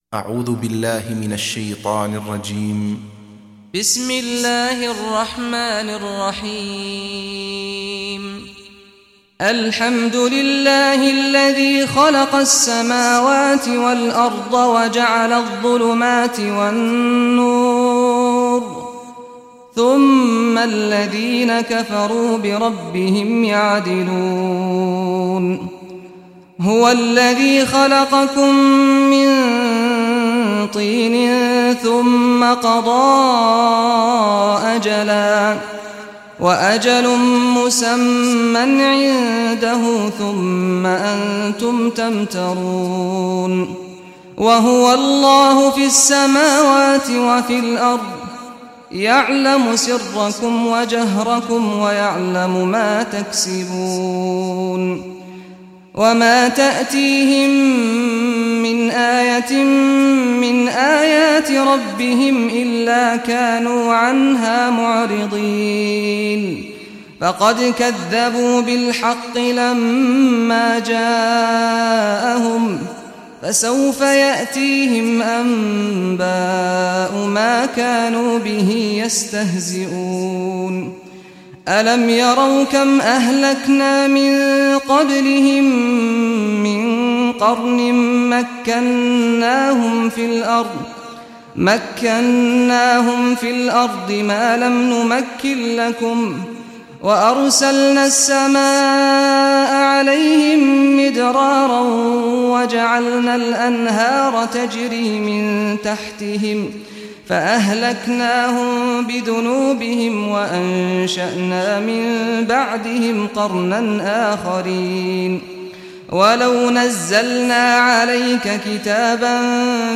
Surah Anaam Recitation by Sheikh Saad al Ghamdi
Surah Anaam, listen online mp3 tilawat / recitation in Arabic in the beautiful voice of Imam Sheikh Saad al Ghamdi.